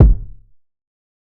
TC2 Kicks25.wav